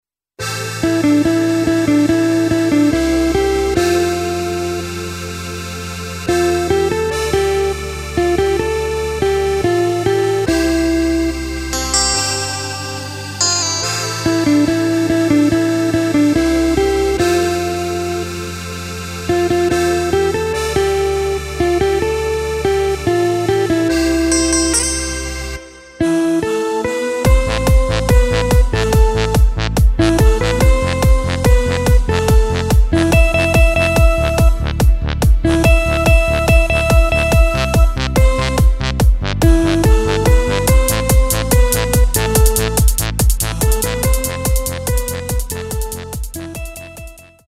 Takt:          4/4
Tempo:         143.00
Tonart:            Am